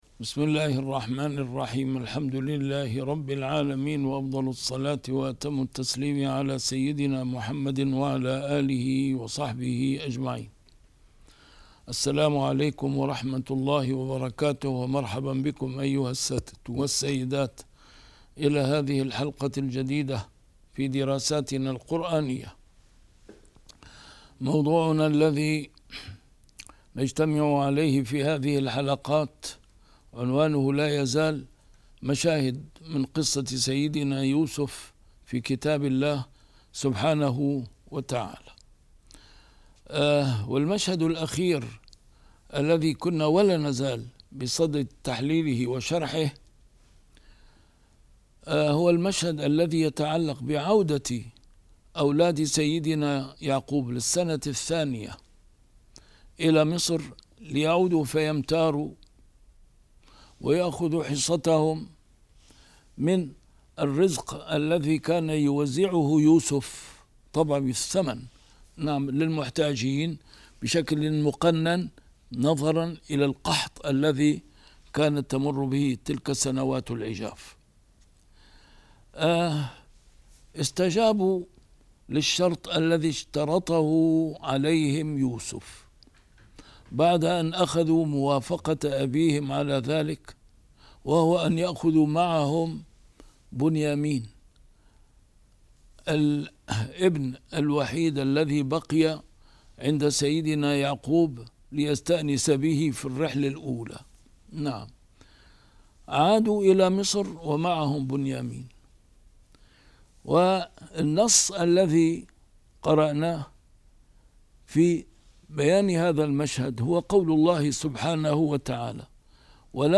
A MARTYR SCHOLAR: IMAM MUHAMMAD SAEED RAMADAN AL-BOUTI - الدروس العلمية - مشاهد من قصة سيدنا يوسف في القرآن الكريم - 8 - لقاء سيدنا يوسف مع إخوته